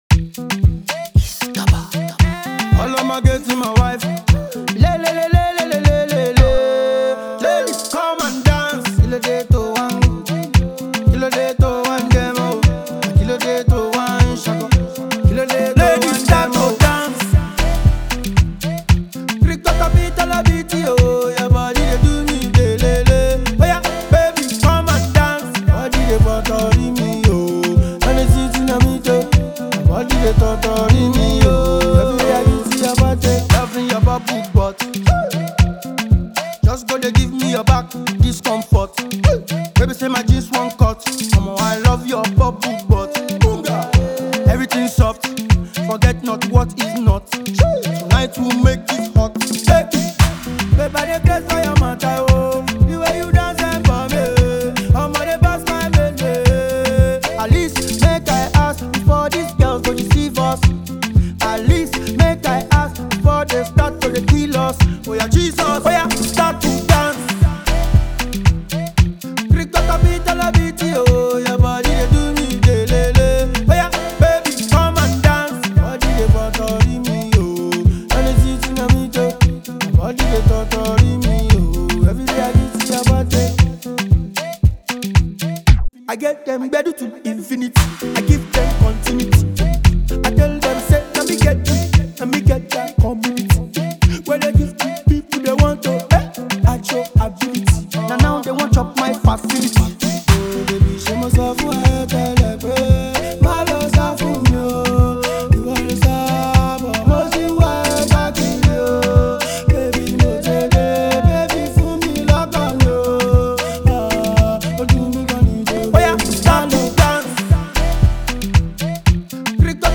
slaps really hard
Heavy!